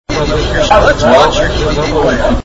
Here are 2 recordings that I made in a fast food restaurant.
Click here to hear the iPad's own speaker playing a recording of me saying "Let's watch your DVD player"
My Super-Auggie Windows XP PC is at the top of this picture, with a nice microphone plugged in, about 2 feet from the iPad.